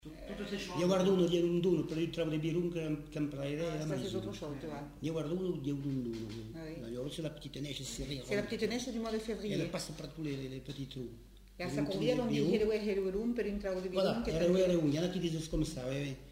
Lieu : Bagnères-de-Luchon
Genre : forme brève
Type de voix : voix d'homme
Production du son : récité
Classification : proverbe-dicton